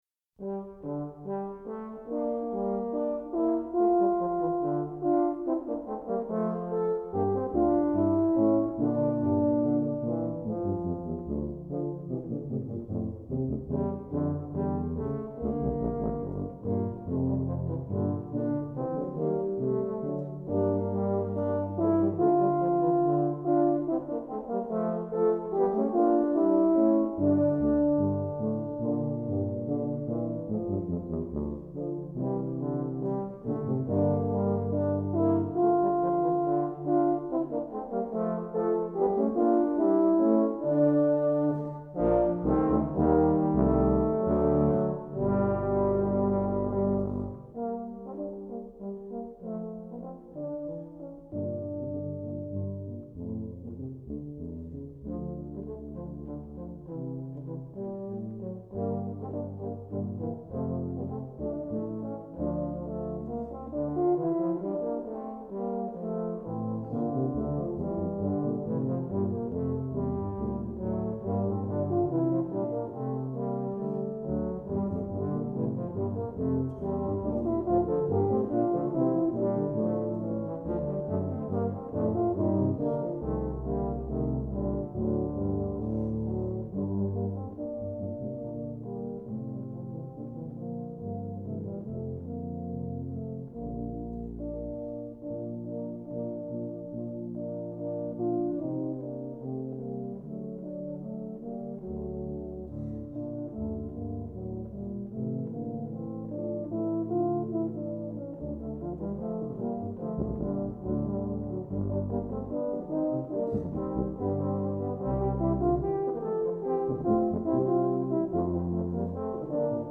For Tuba Quartet (EETT)